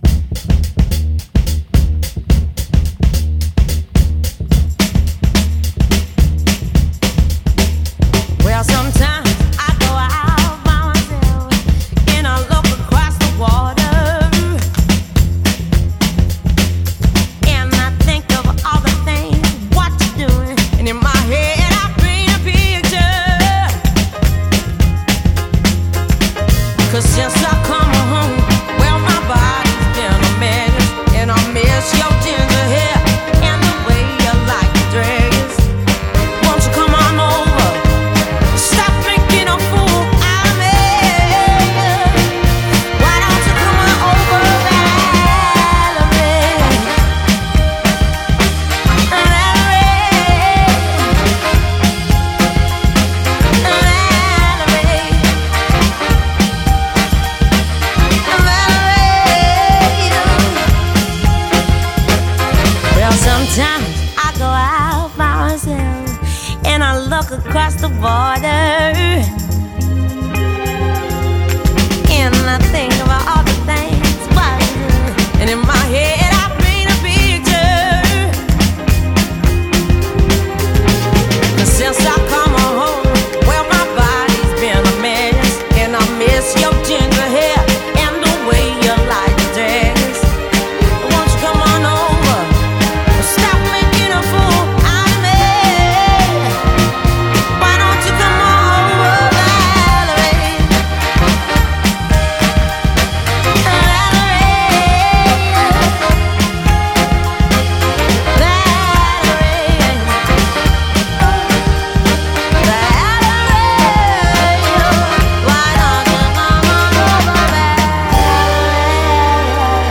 BPM103-110
Audio QualityMusic Cut